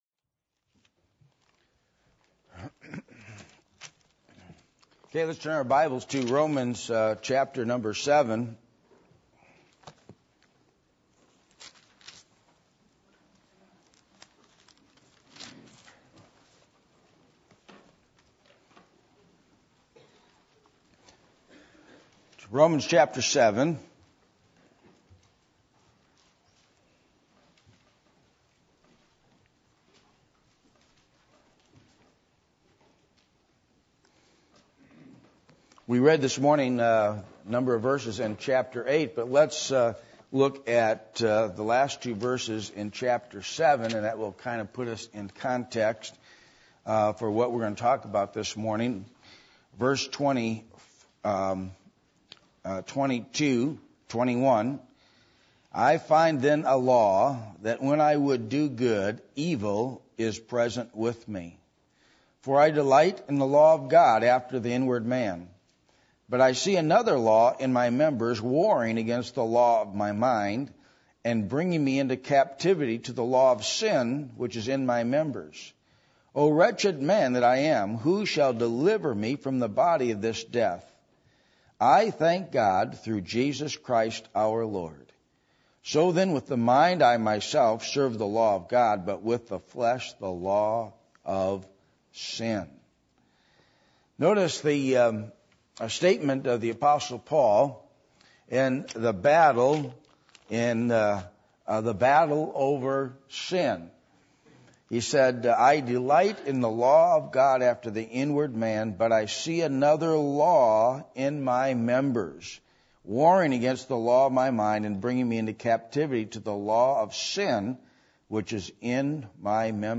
Passage: Romans 7:20-25 Service Type: Sunday Morning %todo_render% « Paul’s Prayer For The Ephesians The Characteristics Of False Teachers